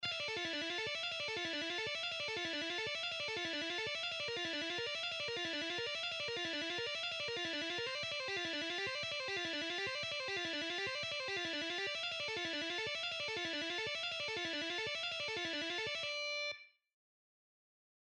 Lesson 5: Symphony X / Michael Romeo Sea Of Lies Tapping
This one is the hardest one today…
Original Speed:
Exercise-5-An-Advance-Tapping-Lick-Michael-Romeo-Symphony-X-Sea-Of-Lies1-3.mp3